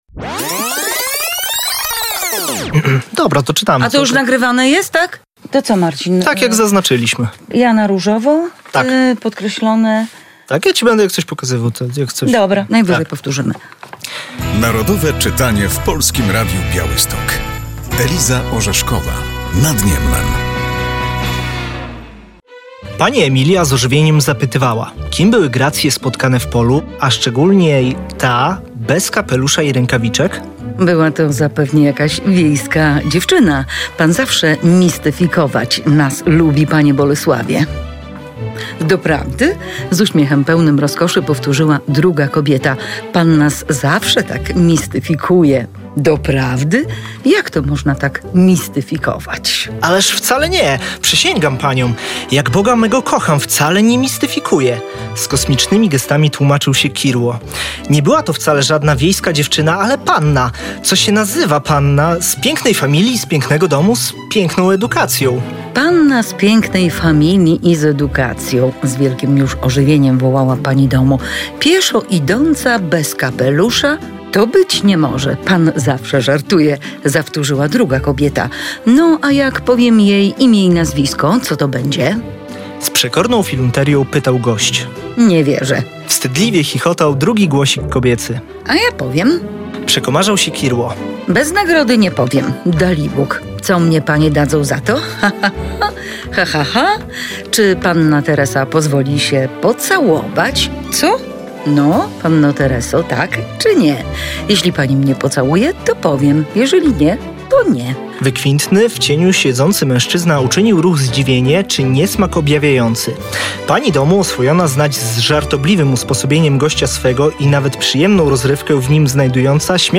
Pozytywistyczną powieść przybliża w tym roku ogólnopolska akcja Narodowe Czytanie. Włączają się do niej także dziennikarze Polskiego Radia Białystok.